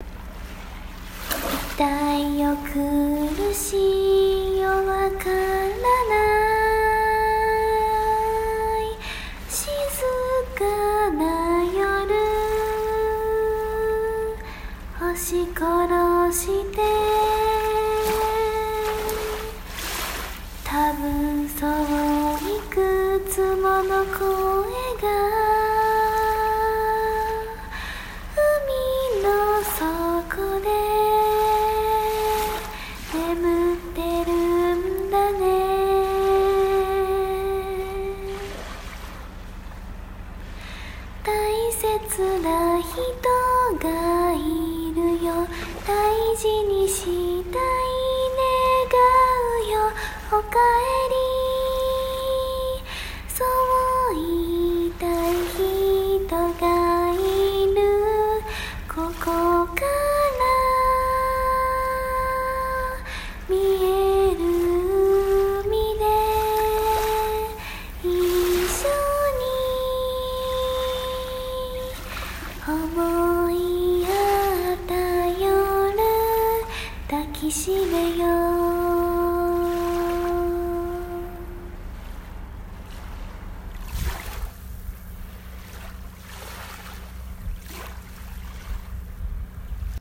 波音💦ちゃぷちゃぷ
瀬戸内海💦💦